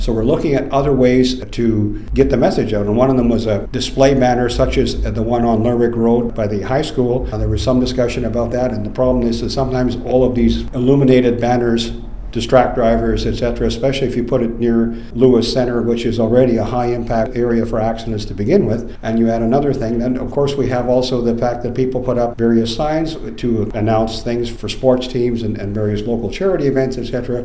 Goat News spoke with Mayor Larry Jangula about this.